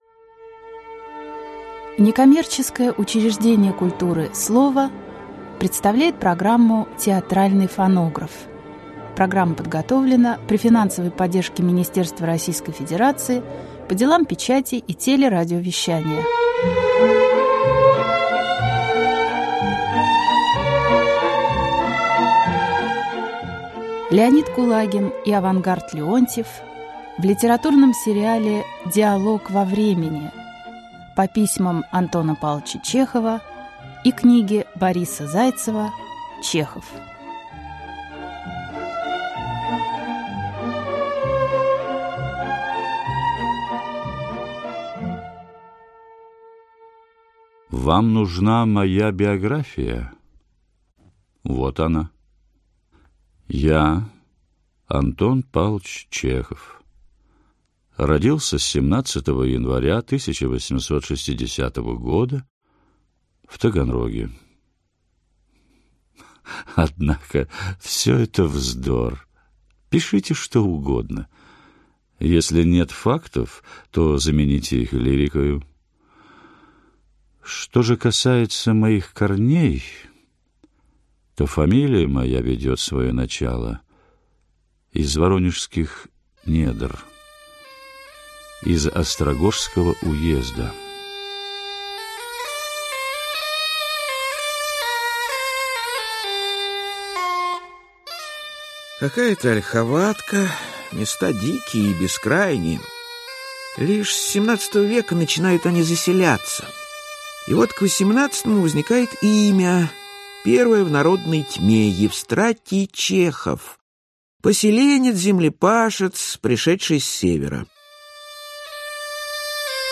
Аудиокнига Диалог во времени. Жизнь и творчество А.П. Чехова | Библиотека аудиокниг
Жизнь и творчество А.П. Чехова Автор Группа авторов Читает аудиокнигу Авангард Леонтьев.